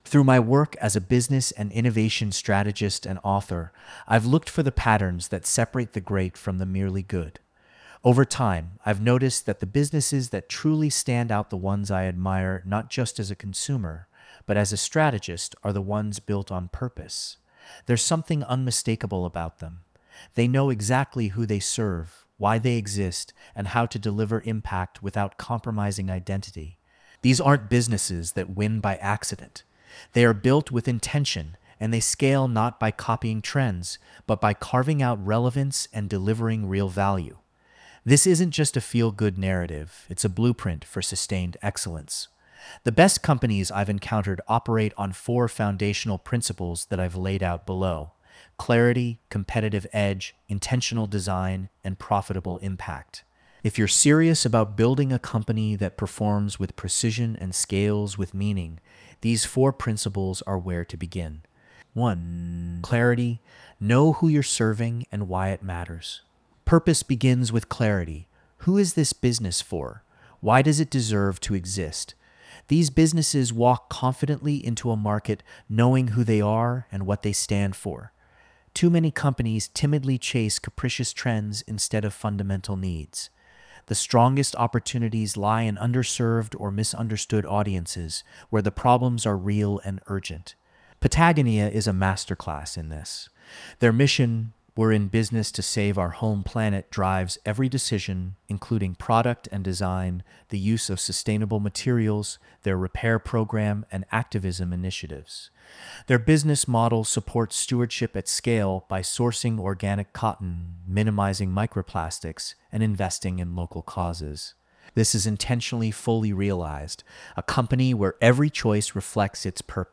This audio was recorded by AI: